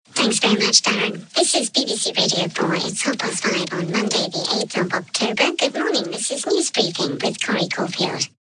More vocoder examples
Vocoded with white noise, formants shifted down.  Scary!